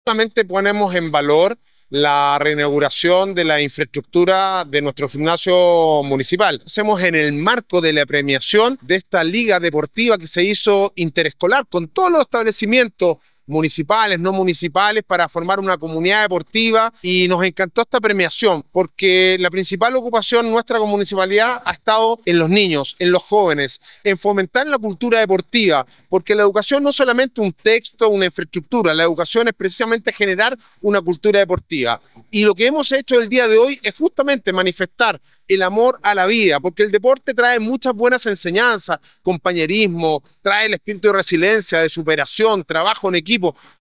“Ponemos en valor la reinauguración de nuestro Gimnasio Municipal”, dijo en la concurrida jornada el alcalde de la comuna, Sebastián Álvarez, quien afirmó que “nos encantó esta premiación, porque la principal ocupación nuestra como Municipalidad ha estado en los niños, en los jóvenes, en fomentar la cultura deportiva, porque la educación no es solamente un texto o una infraestructura, la educación es precisamente una cultura deportiva”.
Alcalde-Sebastian-Alvarez-.mp3